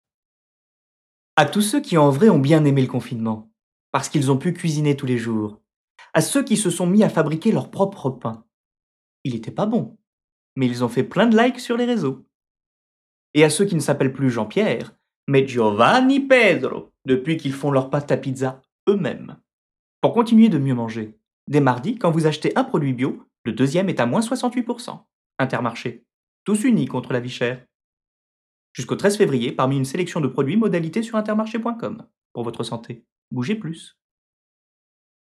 Pub Intermarché